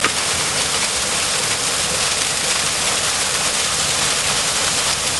Tonos gratis para tu telefono – NUEVOS EFECTOS DE SONIDO DE AMBIENTE de CASCADA
Descargar EFECTO DE SONIDO DE AMBIENTE CASCADA - Tono móvil
cascada.mp3